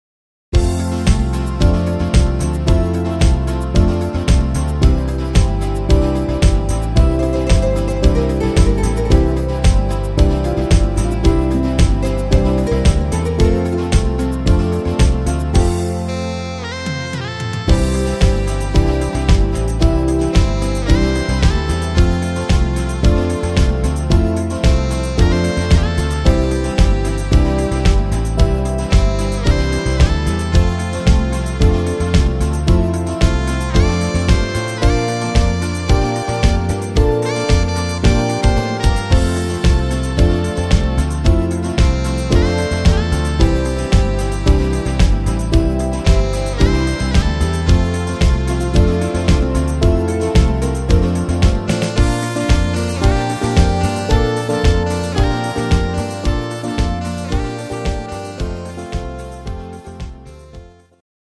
instr. Saxophon